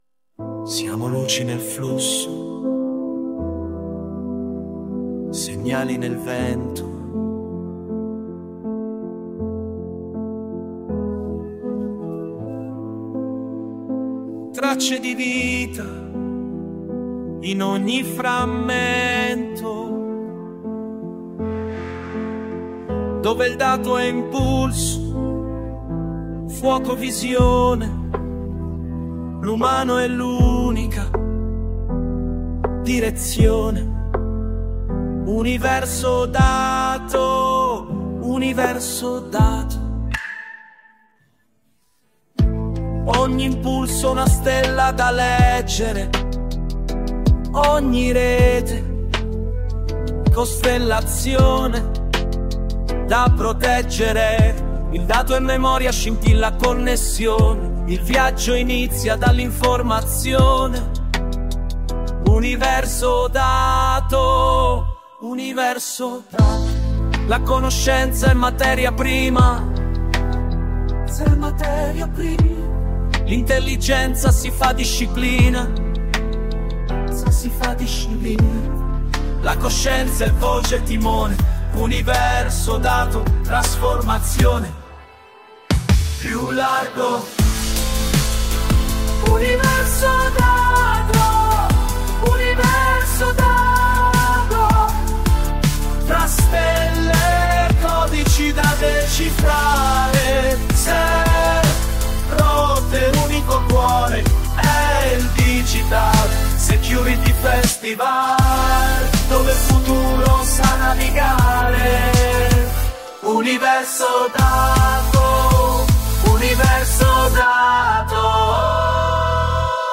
un brano elettronico, intenso e cinematografico